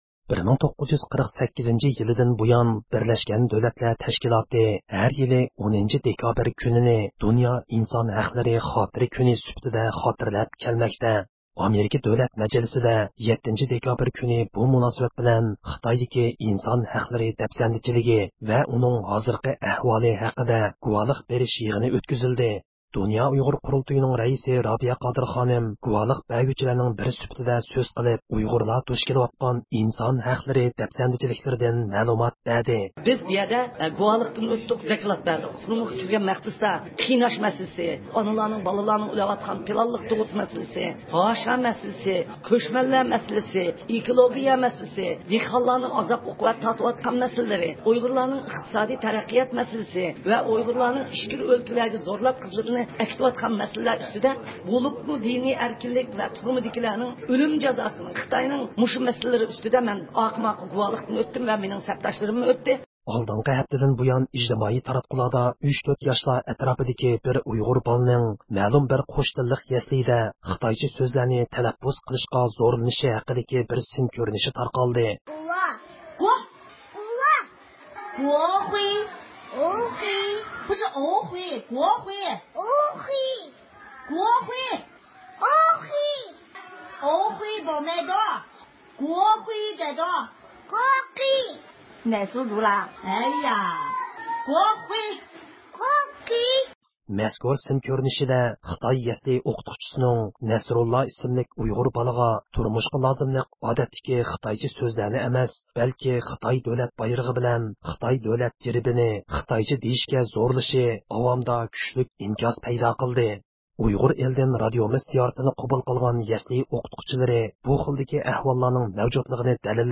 erkin-asiya-radiosi-yengi.jpgئەركىن ئاسىيا رادىئوسى ئۇيغۇر بۆلۈمى ھەپتىلىك خەۋەرلىرى